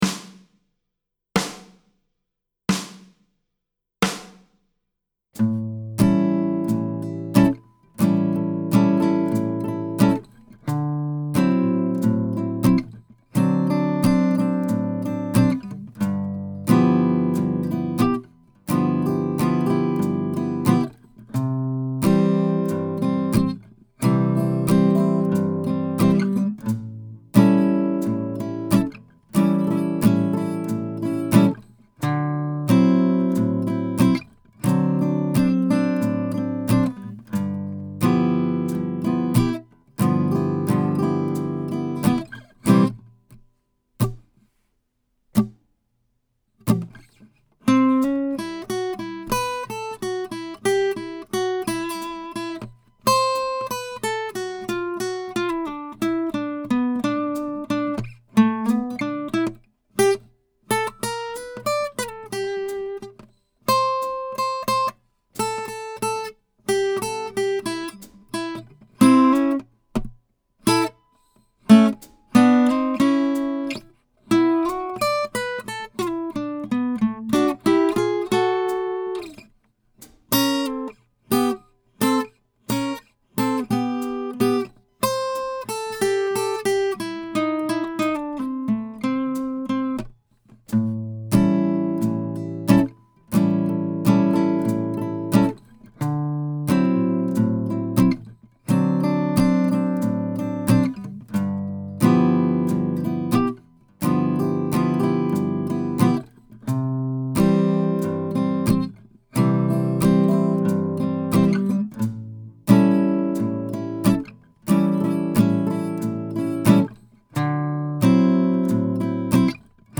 Learn to play blues guitar.
Slow Tempo